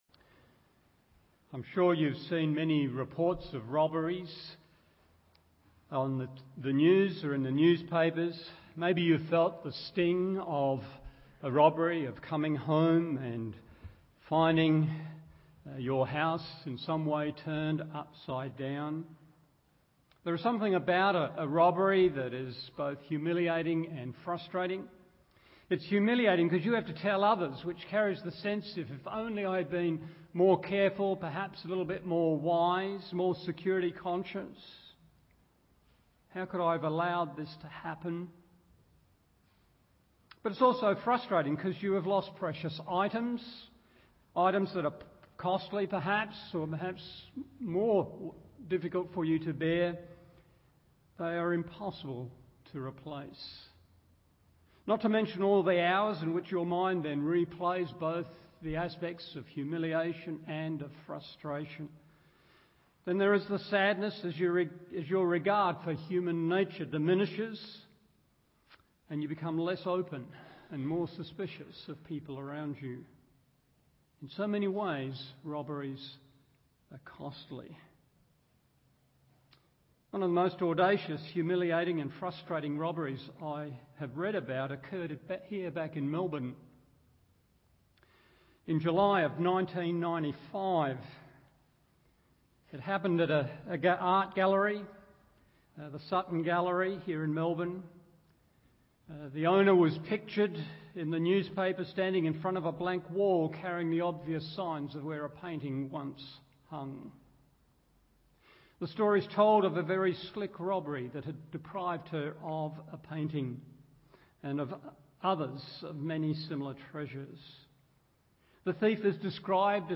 Morning Service Luke 24:1-12 1.